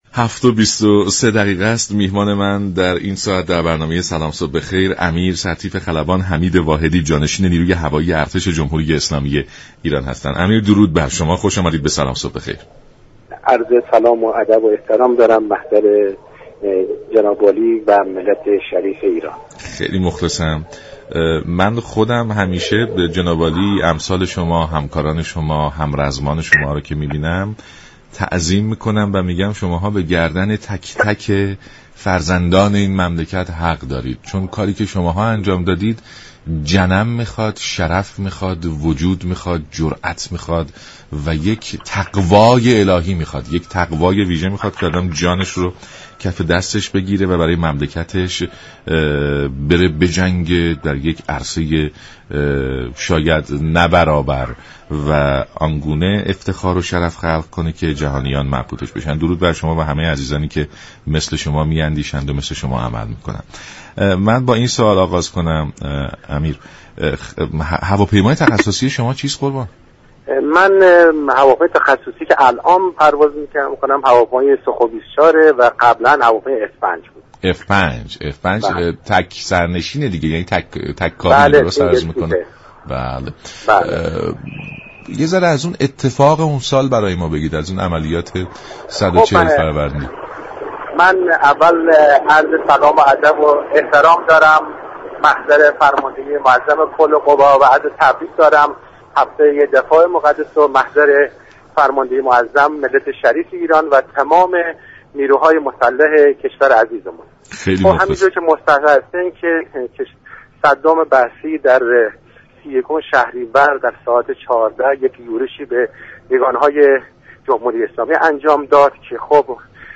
به گزارش شبكه رادیویی ایران، امیر سرتیپ خلبان حمید واحدی جانشین فرمانده نیروی هوایی ارتش جمهوری اسلامی ایران در برنامه «سلام صبح بخیر» رادیو ایران با گرامیداشت هفته دفاع مقدس به عملیات 140 فروندی نیروی هوایی ارتش در دوران دفاع مقدس اشاره كرد و گفت: عملیات غرور آفرین 140 فروندی كه به كمان 99 معروف است نیروی هوایی ارتش سطح پروازی رادارهای دشمن را در تمامی نقاط عراق مورد حمله قرار دادند.